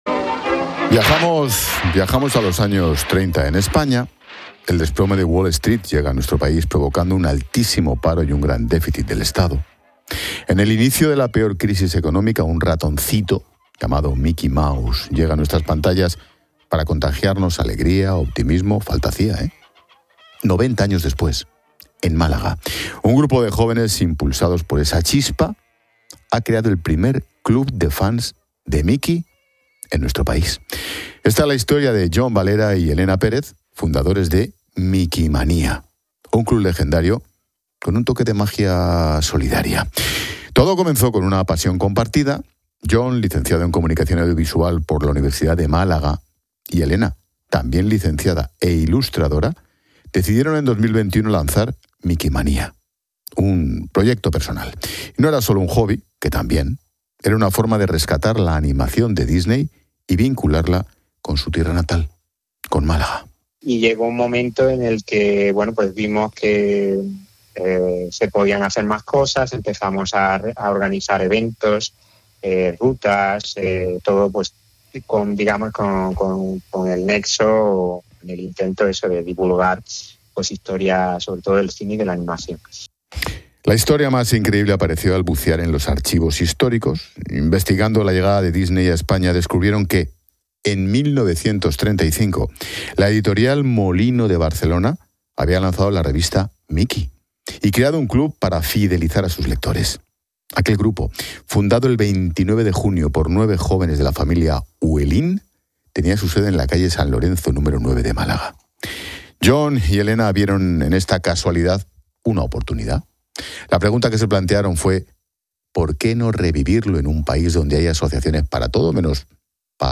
Ángel Expósito cuenta la historia detrás del club de Mickey Mouse de Málaga